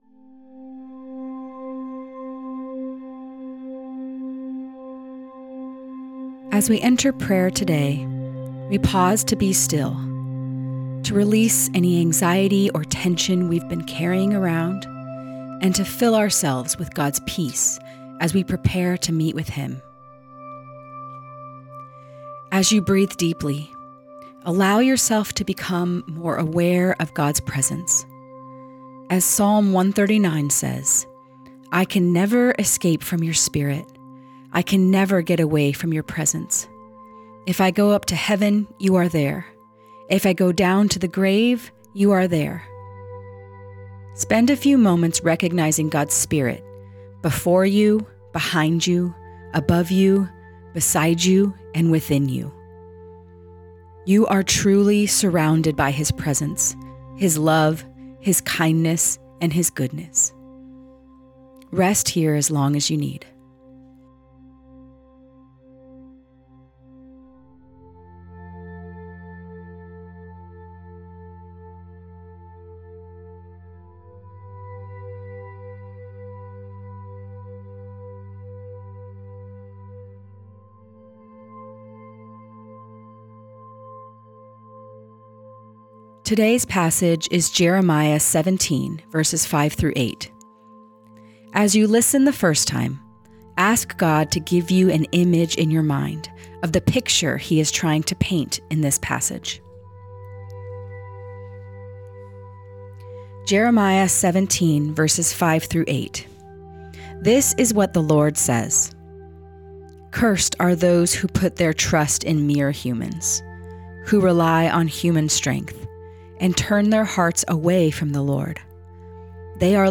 Guided Listening Practice Prepare As we enter prayer today, we pause to be still, to release any anxiety or tension we’ve been carrying around and fill ourselves with God’s peace as we prepare to meet with him.